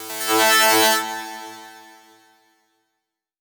VEC3 FX Athmosphere 20.wav